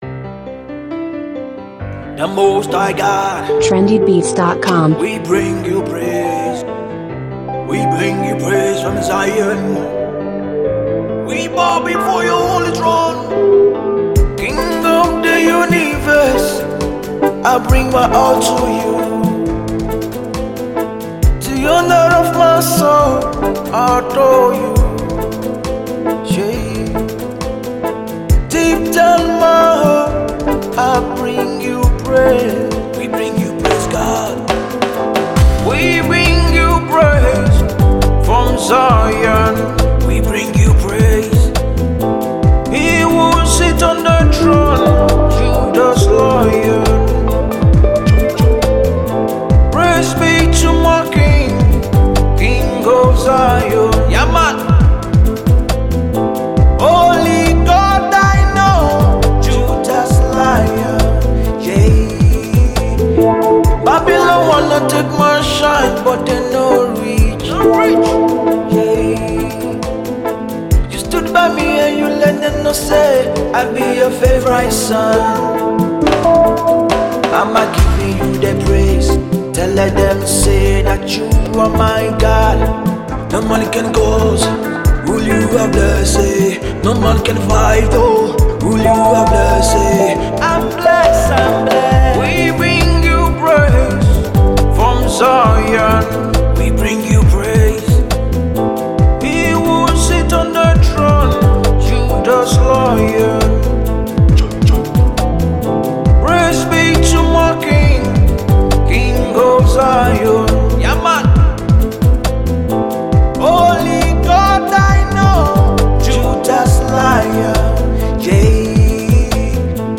gifted gospel crooner